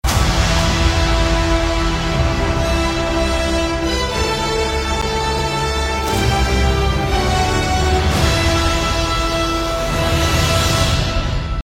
Звуки супергероя
4. Мстители звуковые эффекты